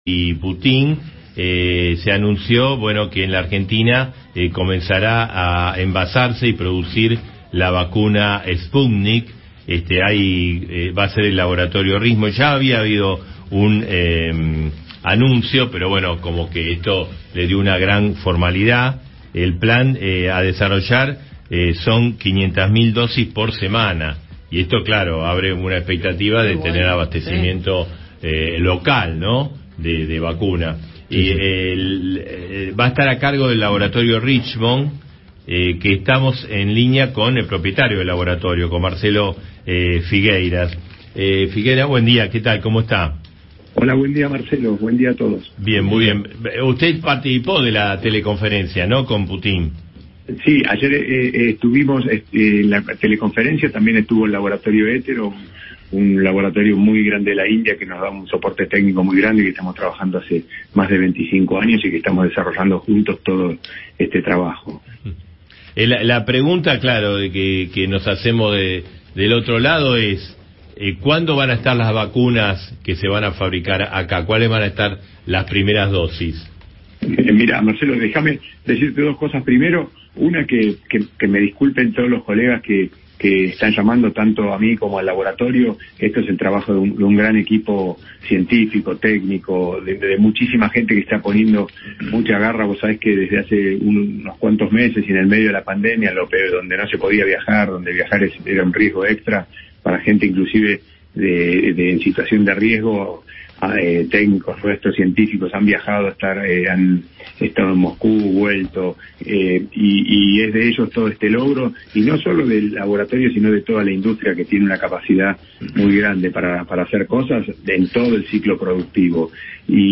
Click to hear radio interview in Spanish.